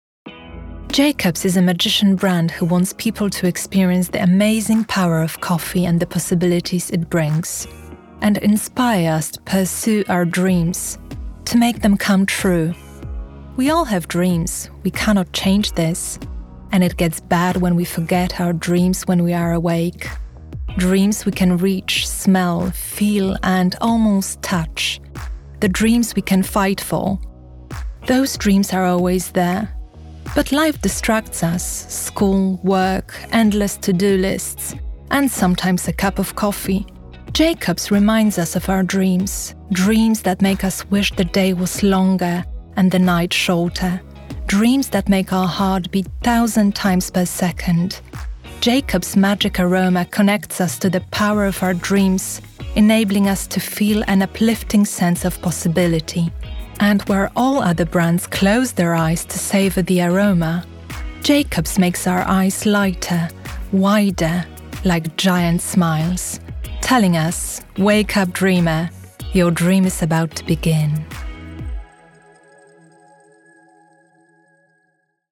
RODE NT1A, cabine vocale entièrement isolée dans un placard. Interface Scarlett 2i2, MacBook Air, Adobe Audition
Mezzo-soprano